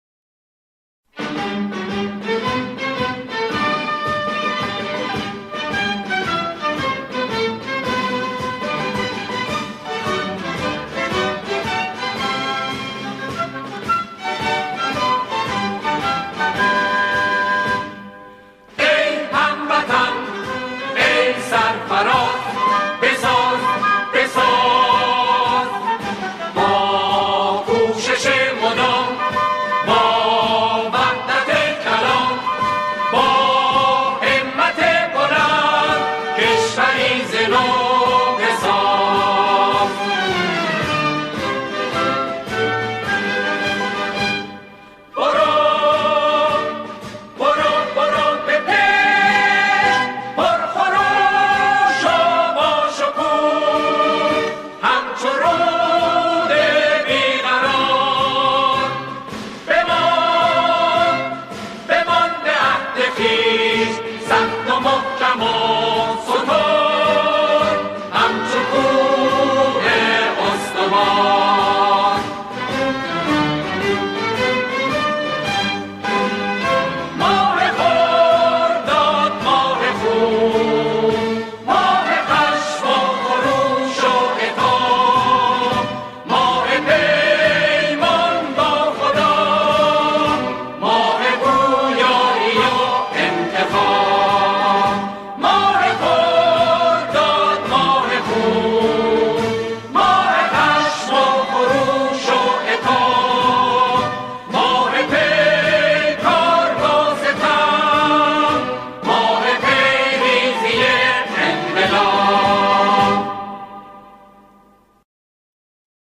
خوانندگان، این قطعه را با شعری عارفانه اجرا می‌کنند.